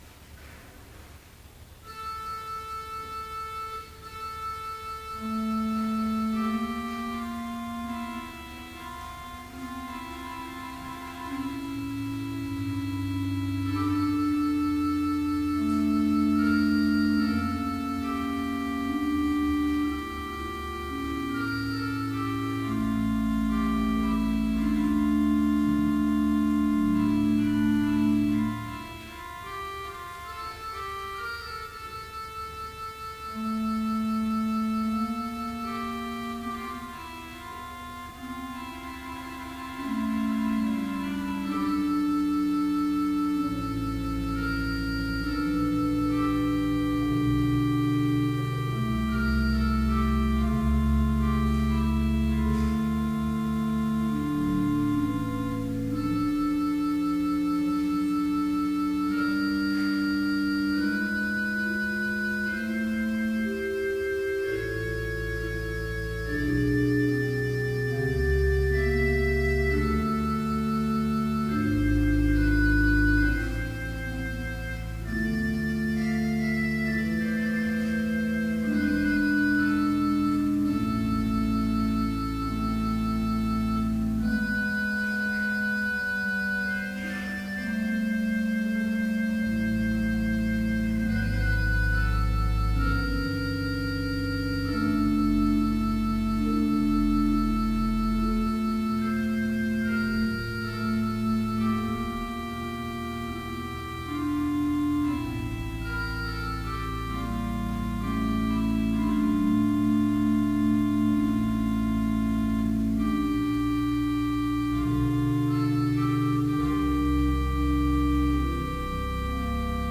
Vespers service in Bethany Lutheran College's chapel
Complete service audio for Evening Vespers - September 19, 2012
Lesson I Corinthians 7:29-31 Homily Choir Canticle: Magnificat in D Luke1:46-55 - J. Pachelbel, 1653-1706 Magnificat anima mea Dominum.